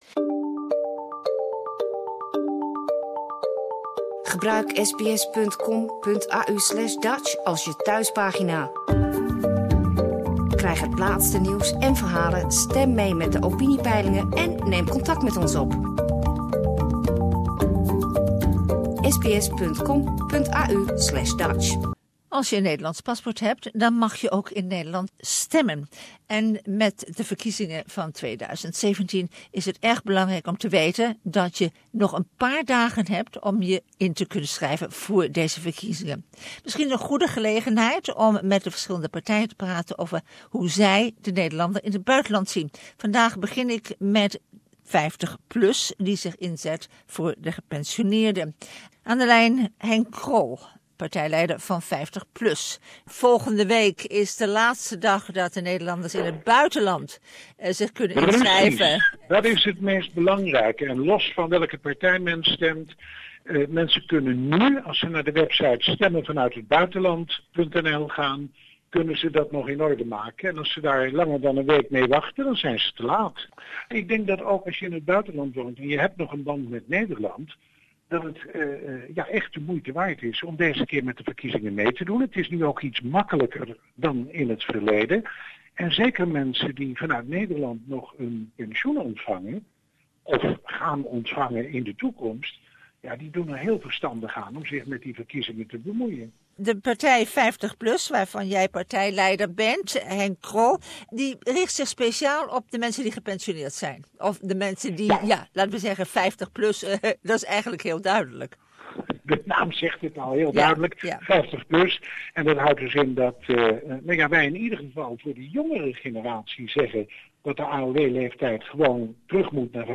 If you have a Dutch passport you can vote in the 2017 Dutch election. Issues such as dual nationality, retirement age, indexing of pensions and death duties do concern us and Henk Krol from the 50plus party explains how they think about these subjects.